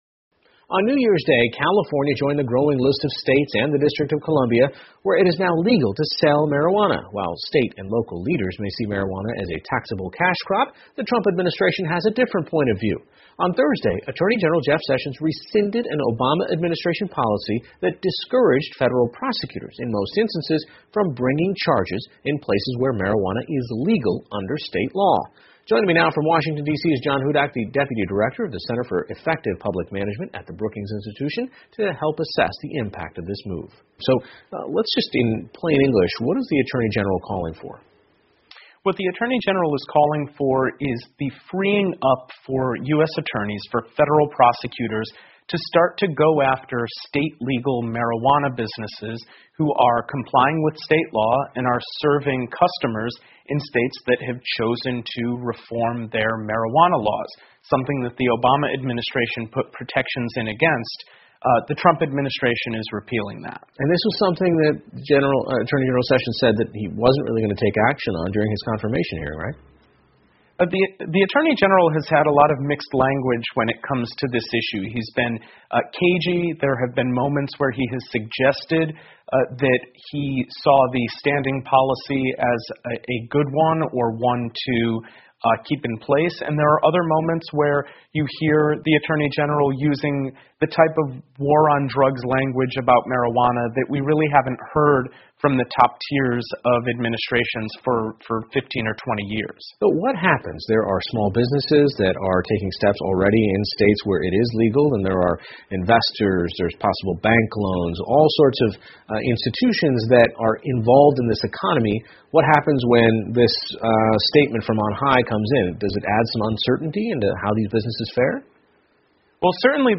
PBS高端访谈: 听力文件下载—在线英语听力室